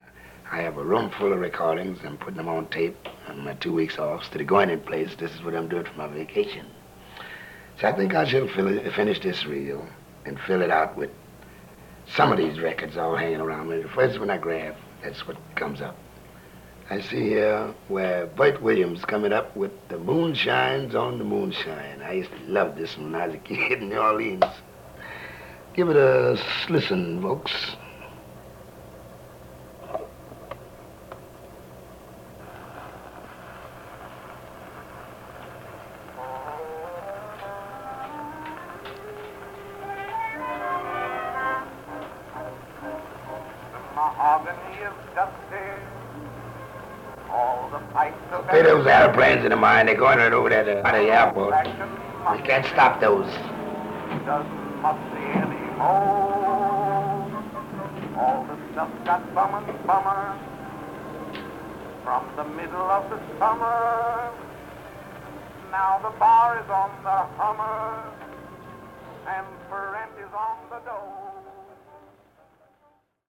Here he is explaining just that on a tape from the 1950s where he dubs recordings from one of his heroes, the pioneering African American entertainer Bert Williams. Armstrong, sitting by himself and speaking to an imaginary audience who might one day hear this (that day is today), announces that he’s spending his vacation copying records. We’ve left to the opening of the Williams record intact for more “That’s My Home” flavor as Louis points the listener’s attention to the airplanes flying overhead to nearby LaGuardia Airport.